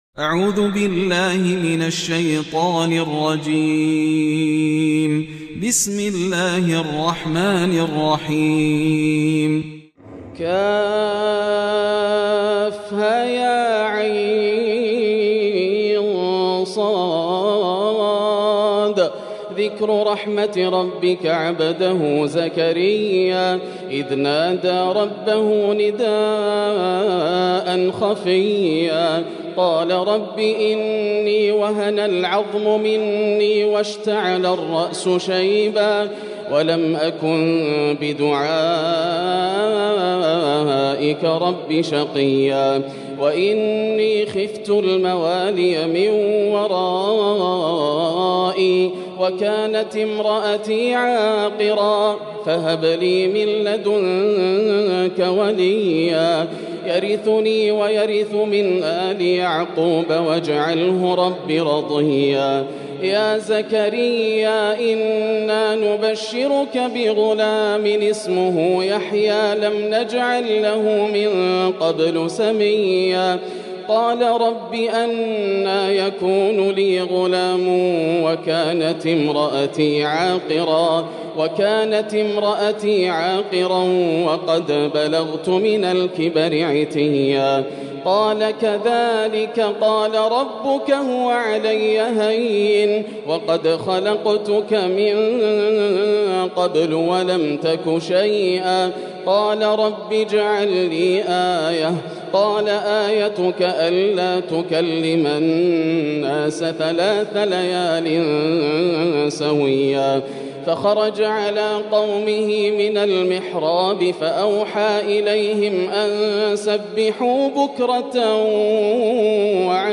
تراويح ليلة 30 رمضان 1441هـ سورة مريم كاملة | Taraweeh 30 th night Ramadan 1443H Surah Maryam > تراويح الحرم المكي عام 1441 🕋 > التراويح - تلاوات الحرمين